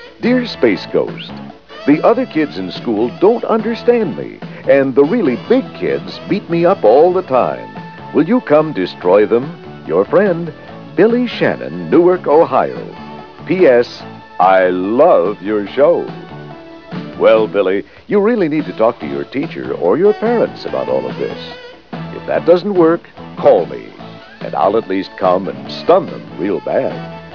In this pilot, Space Ghost was voiced by none other than Gary Owens.